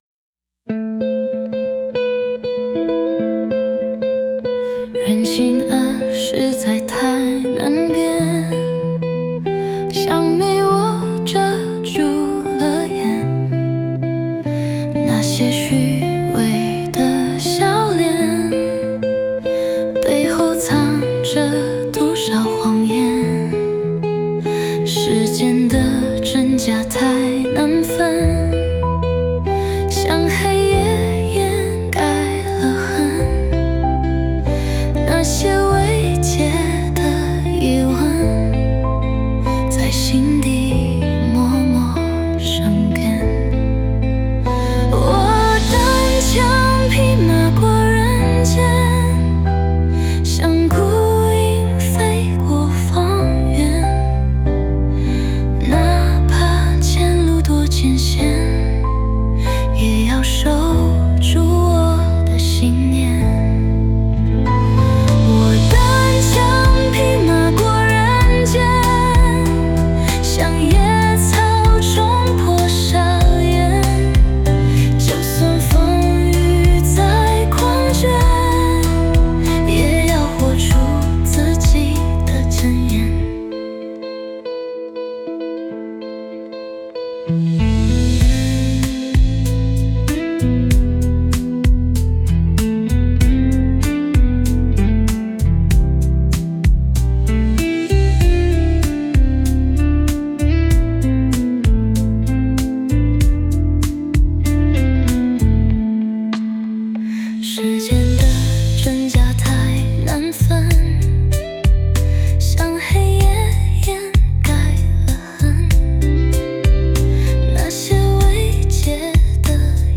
在线试听为压缩音质节选